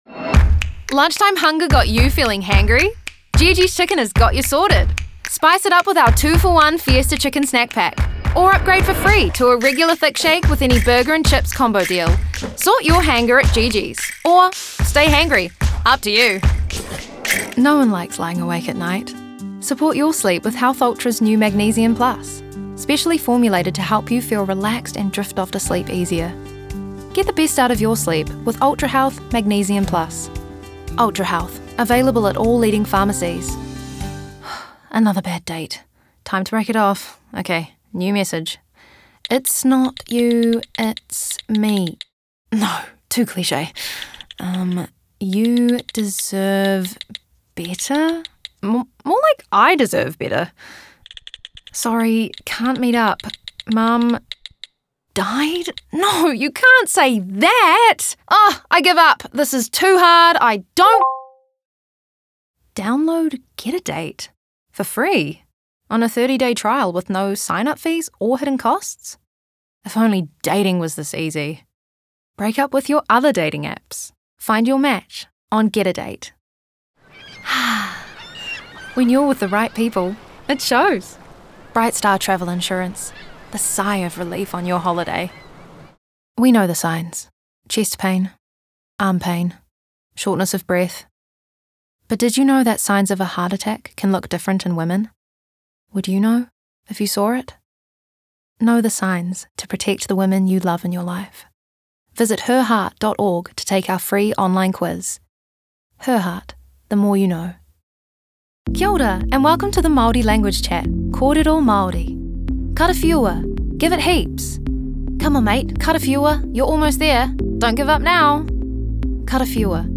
Demo
Young Adult, Adult
Has Own Studio
Her voice spans from warm and smooth to friendly and energetic, maintaining a sense of trustworthiness, sophistication, and eloquence that suits a variety of voice work.